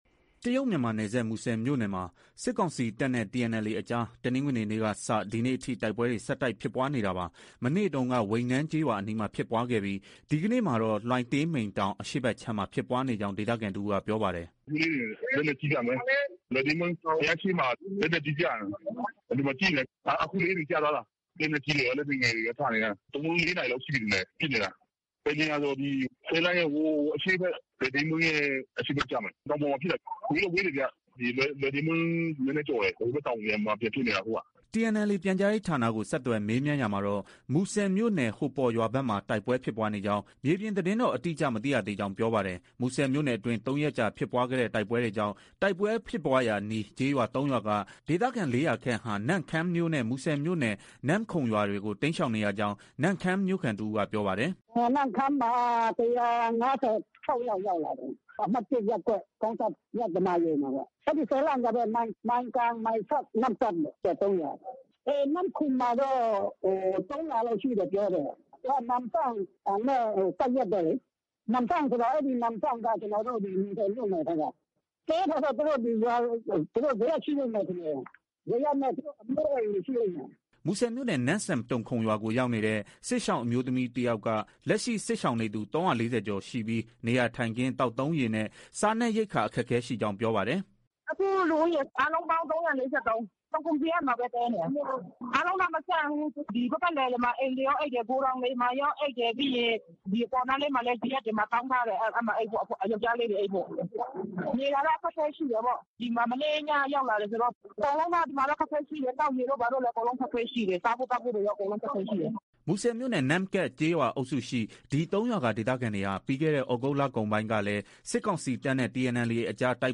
တရုတ်-မြန်မာနယ်စပ် မူဆယ်မြို့နယ်မှာ စစ်ကောင်စီတပ်နဲ့ TNLA အကြား တနင်္ဂနွေနေ့ကစ ဒီကနေ့အထိ တိုက်ပွဲတွေ ဆက်တိုက်ဖြစ်ပွားနေတာပါ။ မနေ့တုန်းက ဝိန်နန်းကျေးရွာအနီးမှာဖြစ်ပွားခဲ့ပြီး ဒီကနေ့မှာတော့ လွိုင်တေးမိန်းတောင် အရှေ့ဘက်ခြမ်းမှာ ဖြစ်ပွားနေကြောင်း ဒေသခံတစ်ဦးက ပြောပါတယ်။
မူဆယ်မြို့နယ် နမ့်စန့်တုမ်ခုမ်ရွာကို ရောက်နေတဲ့ စစ်ရှောင်အမျိုးသမီးတယောက်က လက်ရှိ စစ်ရှောင်နေသူ ၃၄၀ကျော်ရှိပြီး နေရာထိုင်ခင်း၊ သောက်သုံးရေနဲ့ စားနပ်ရိက္ခာအခက်အခဲ ရှိကြောင်း ပြောပါတယ်။